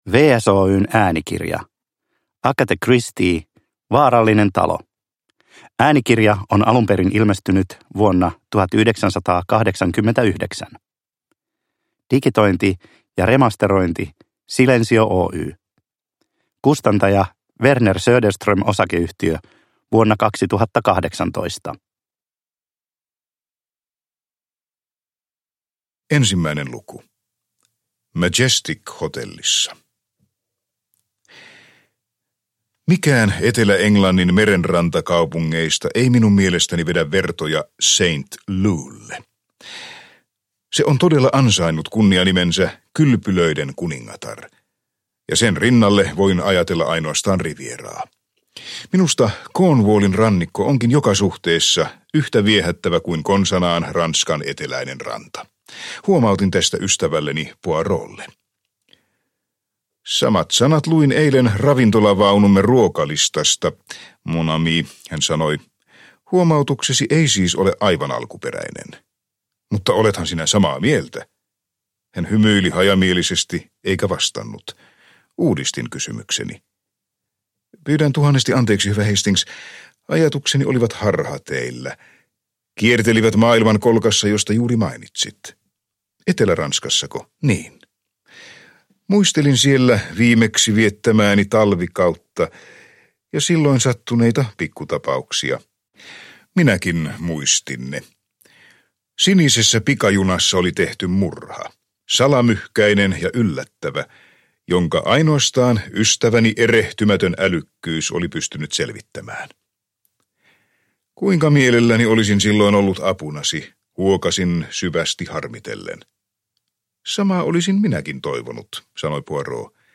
Vaarallinen talo – Ljudbok – Laddas ner